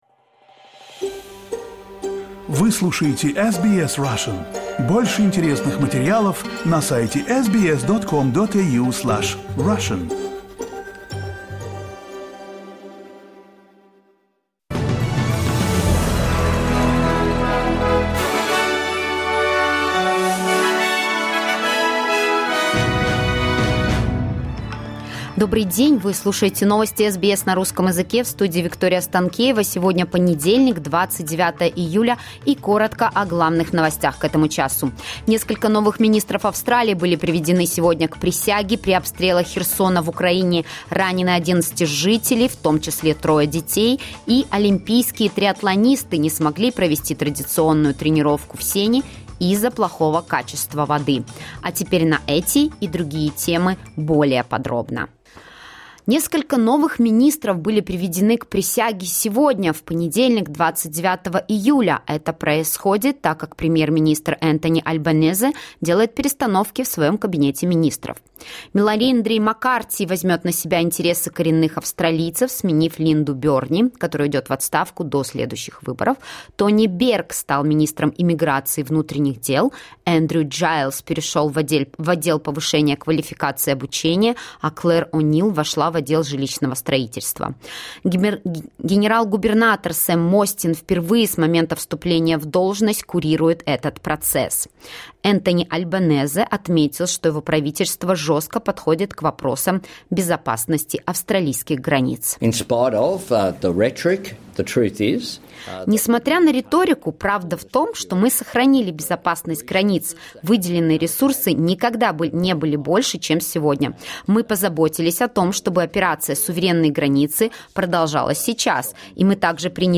Latest news headlines in Australia from SBS Russian